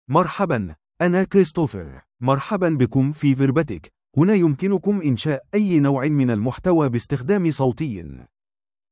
MaleArabic (Standard)
Christopher is a male AI voice for Arabic (Standard).
Voice sample
Listen to Christopher's male Arabic voice.
Male
Christopher delivers clear pronunciation with authentic Standard Arabic intonation, making your content sound professionally produced.